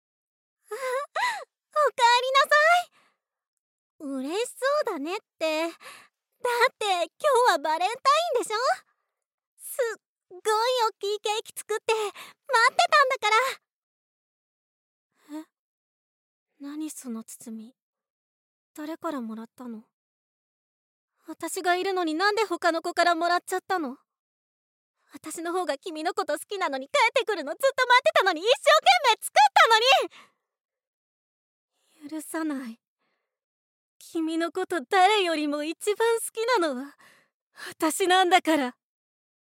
ボイスサンプル
ヤンデレ